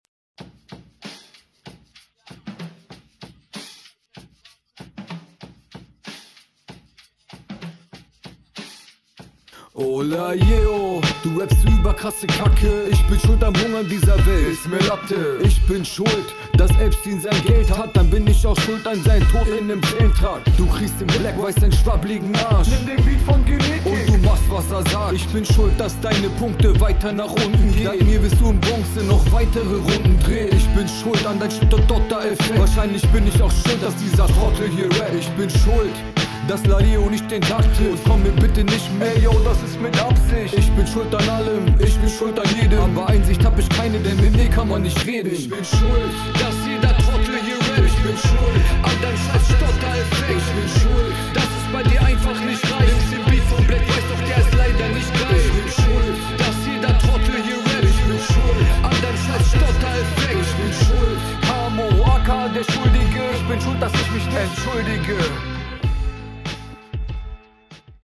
Audio clippt am Anfang bisschen.